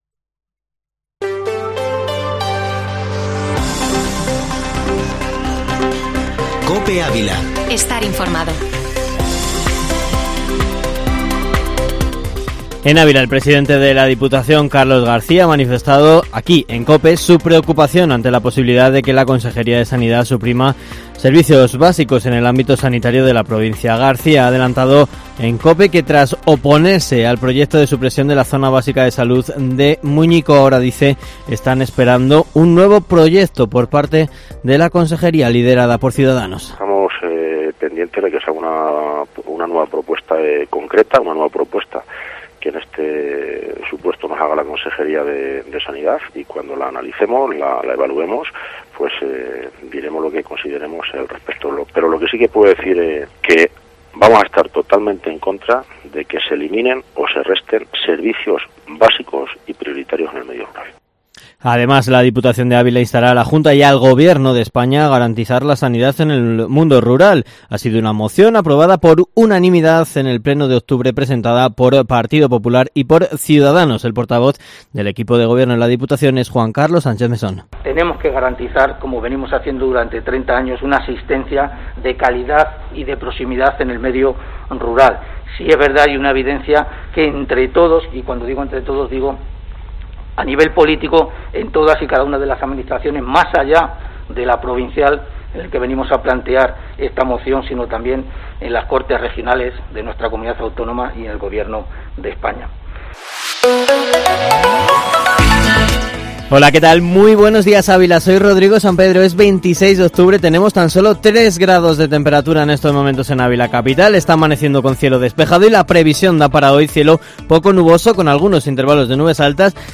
Informativo Matinal Herrera en COPE Ávila -26-oct